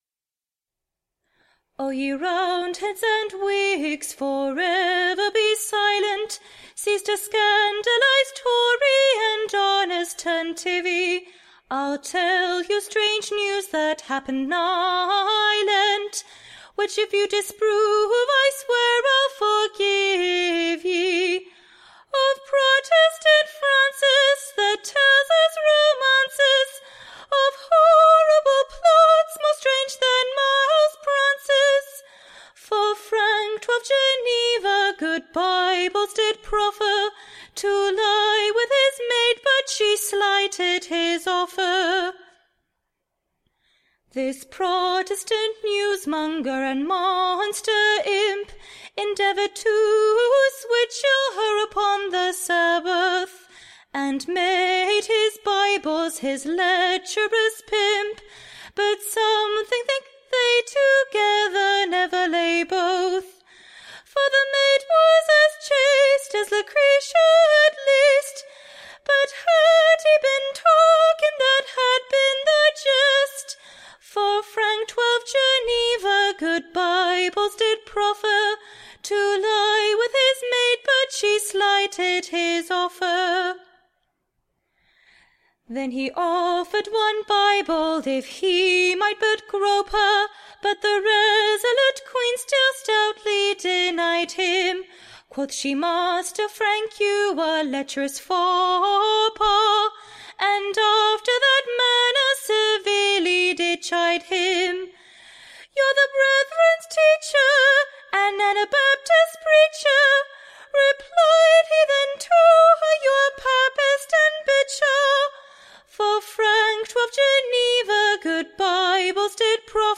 Recording Information Ballad Title THE / Leacherous ANABAPTIST: / OR, / The Dipper Dipt. / A New Protestant Ballad.